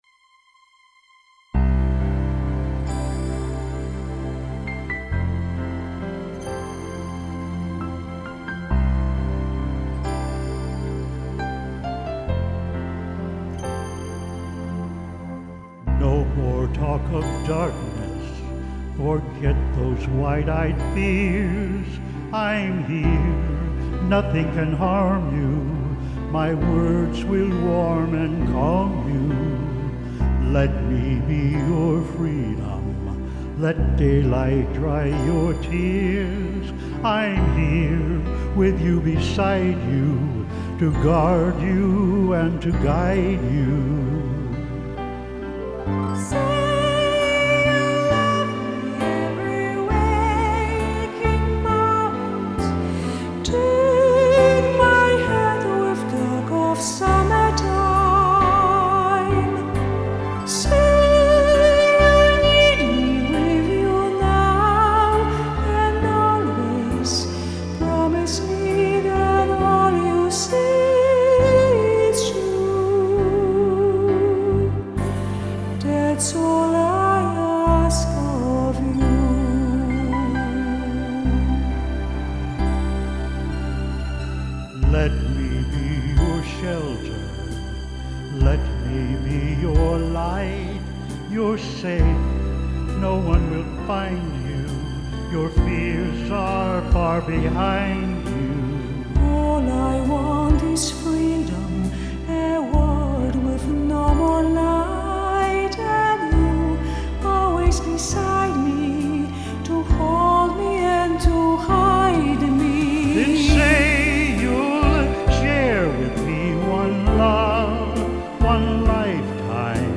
BROADWAY